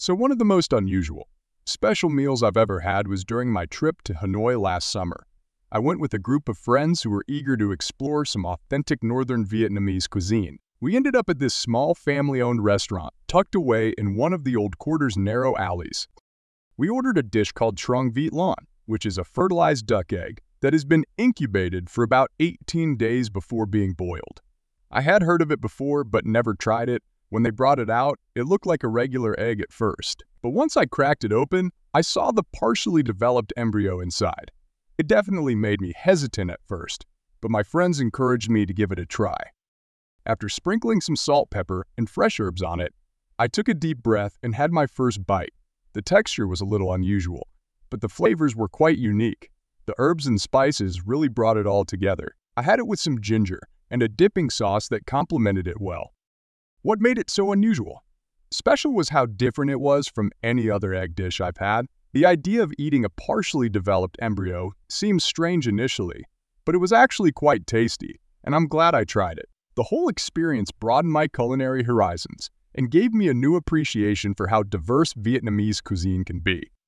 Trong bài viết này, Mc IELTS chia sẻ câu trả lời mẫu band 8.0+ từ cựu giám khảo IELTS, kèm theo các câu hỏi mở rộng kèm theo các câu hỏi mở rộng và bản audio từ giáo viên bản xứ để bạn luyện phát âm, ngữ điệu và tốc độ nói tự nhiên.